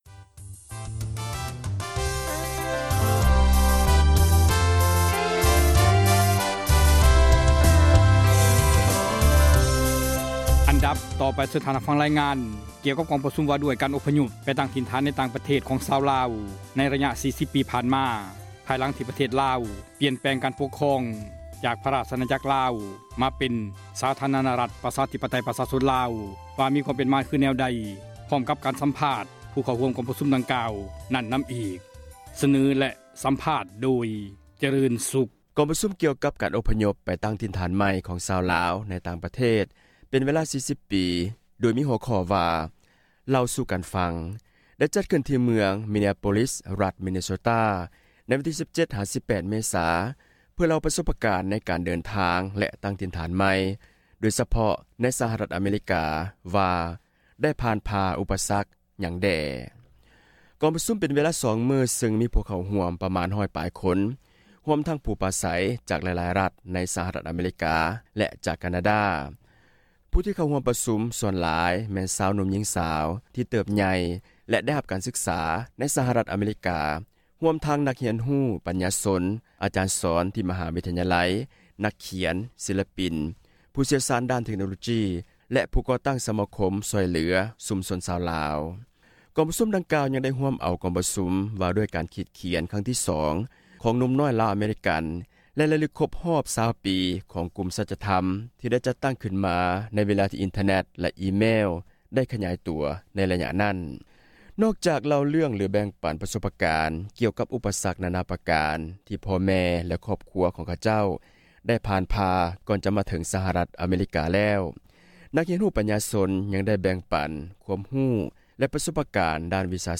ກອງປະຊຸມ ວ່າດ້ວຍ ການ ອົພຍົບ ໄປ ຕັ້ງ ຖິ່ນຖານ ໃນ ຕ່າງ ປະເທດ ຂອງ ຊາວລາວ ໃນ ລະຍະ 40 ປີ ຜ່ານມາ ພາຍຫລັງ ທີ່ ປະເທດ ລາວ ປ່ຽນແປງ ການ ປົກຄອງ ຈາກ ພຣະ ຣາຊອານາຈັກ ລາວ ມາເປັນ ສປປ ລາວ ໃນປີ 1975, ວ່າ ມີຄວາມ ເປັນມາ ແນວໃດ ພ້ອມກັບ ການ ສັມພາດ ຜູ່ ເຂົ້າຮ່ວມ ກອງ ປະຊຸມ. ຈັດມາ ສເນີ ທ່ານ ໂດຍ